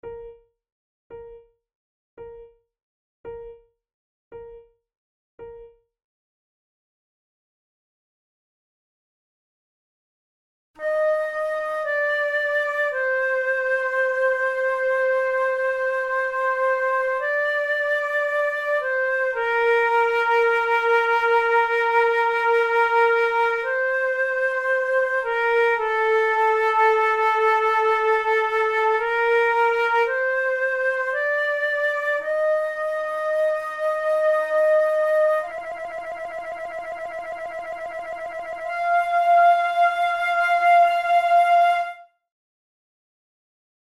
This very short Adagio is the third movement of a sonata in B-flat major for two flutes by the German Baroque composer and music theorist Johann Mattheson.
Categories: Baroque Sonatas Written for Flute Difficulty: easy